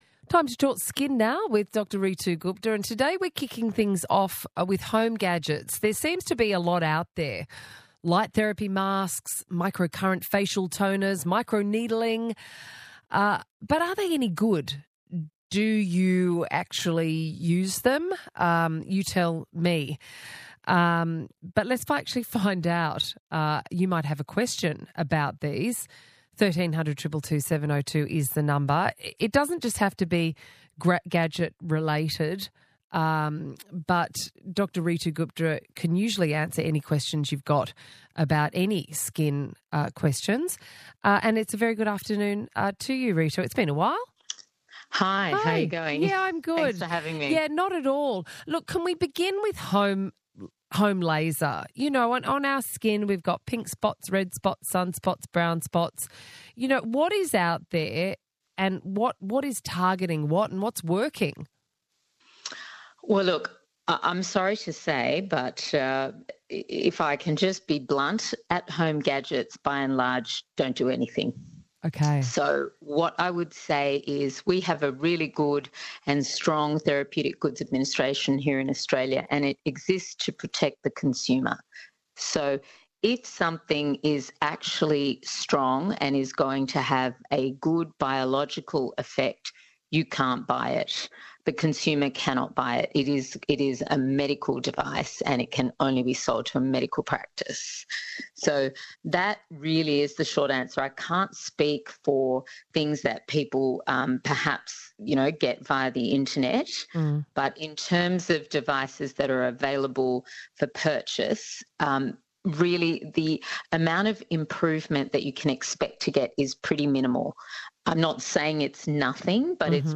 Listen to the full ABC Radio segment below.